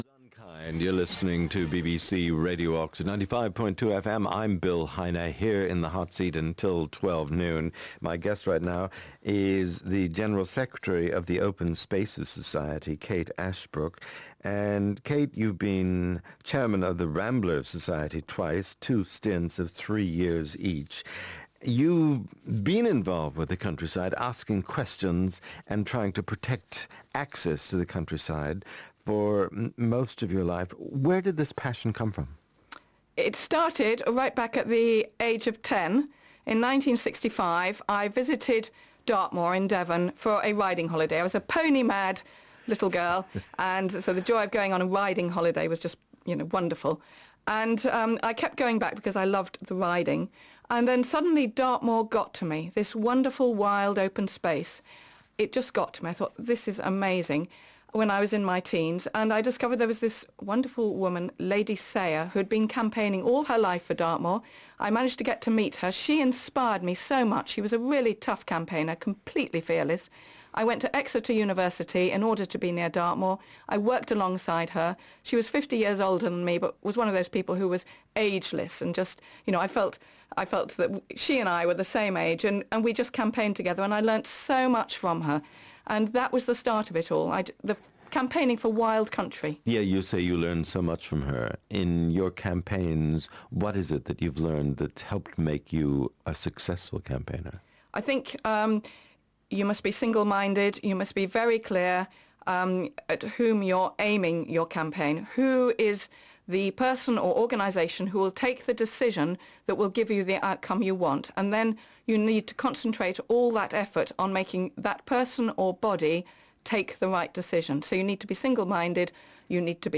BBC Radio Oxford interview
radio-interview.mp3